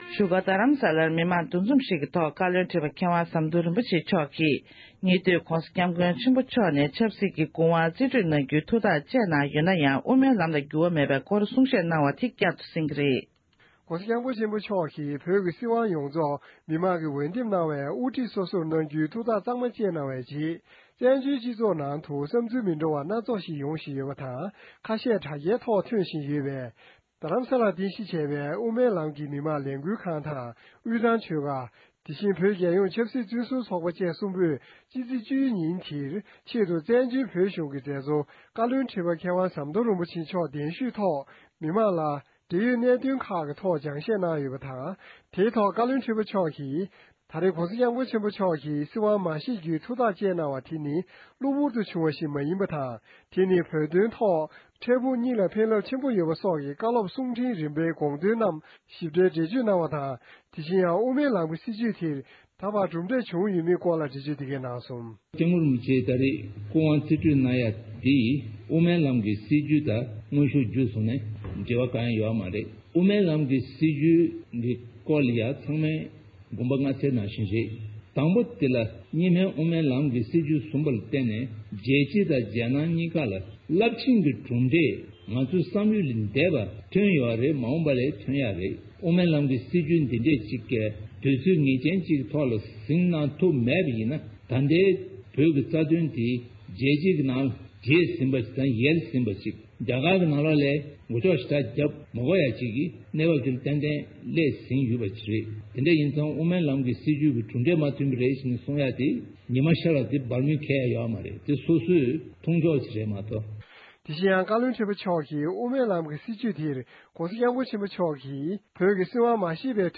གནས་ཚུལ་ཕྱོགས་སྒྲིག་ཞུས་པར་གསན་རོགས༎